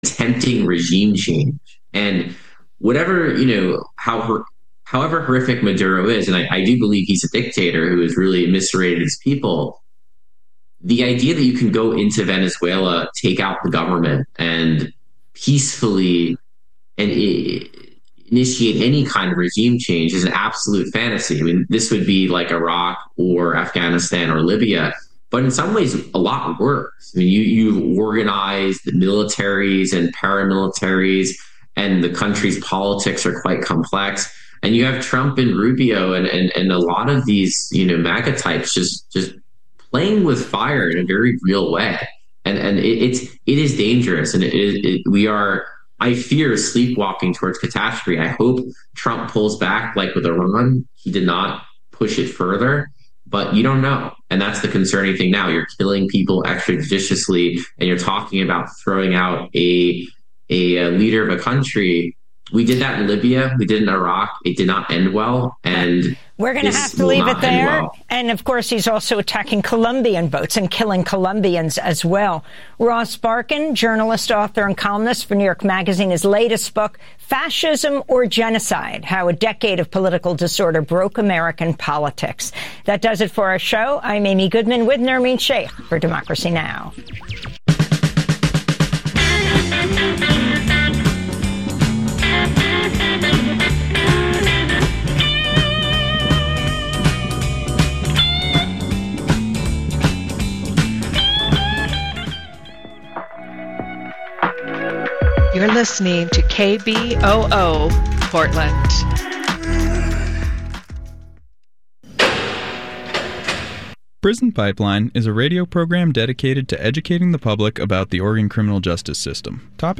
Oregon secretary and an Oregon congresswoman team up for a town hall getting lots of questions about ICE and the National Guard coming nto the city.